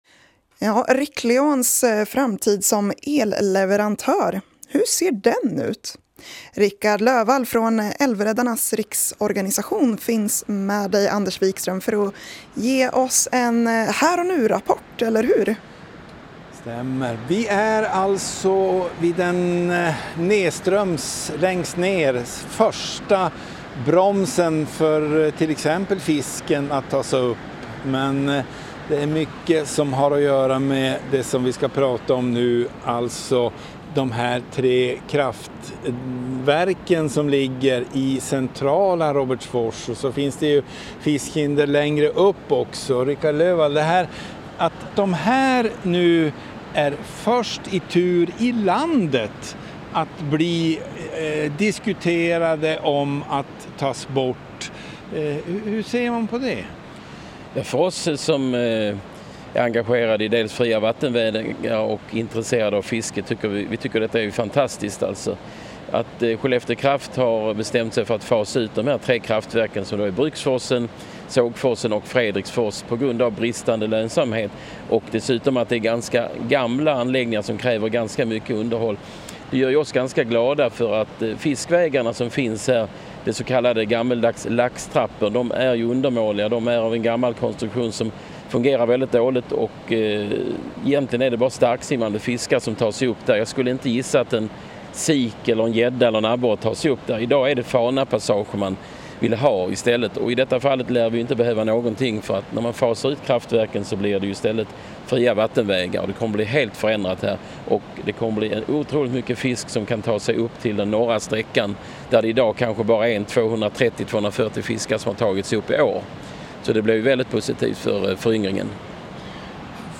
Platsen är Bruksforsens kraftverk i Robertsfors